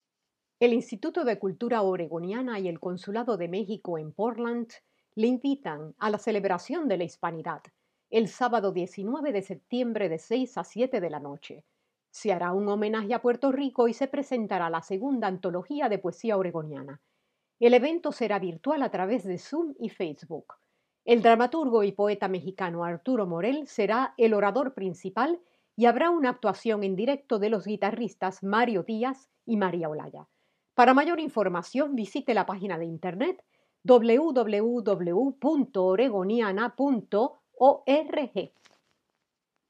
Hispanidad-2020-Anuncio-de-Radio.m4a